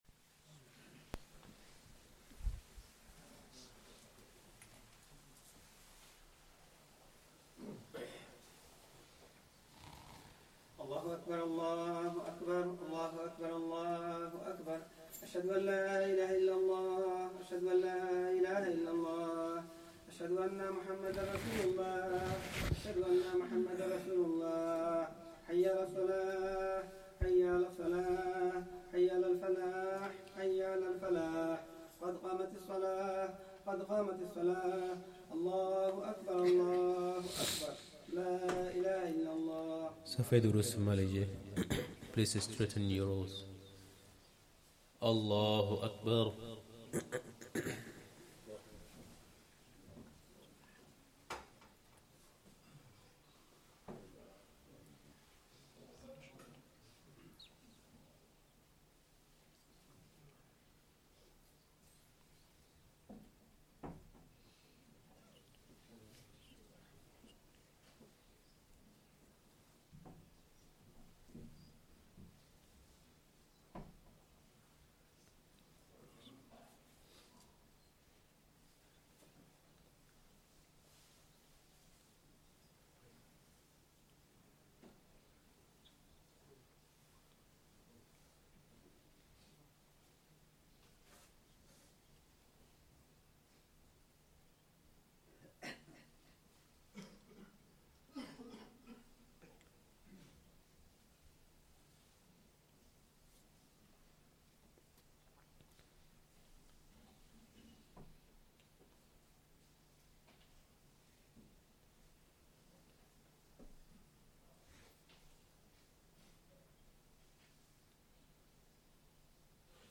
Asr salah + Bayaan
Masjid Adam, Ilford Recording Date